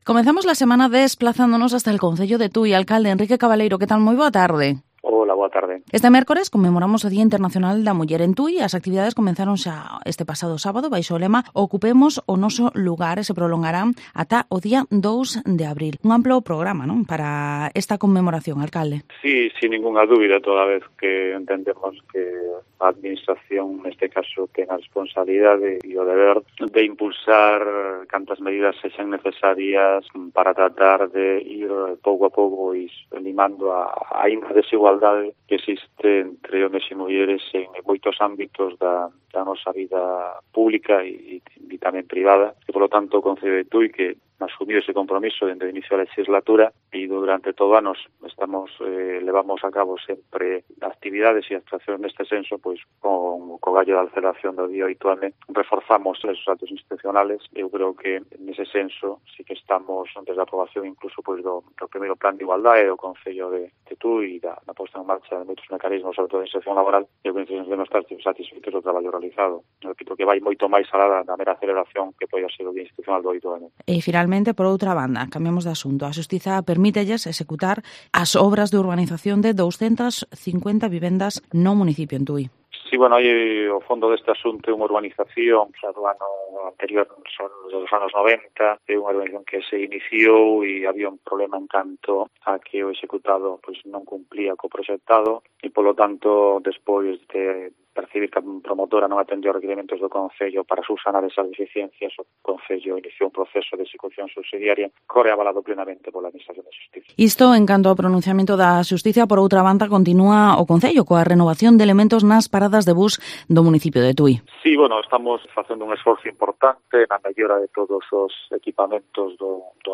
Entrevista al Alcalde de Tui, Enrique Cabaleiro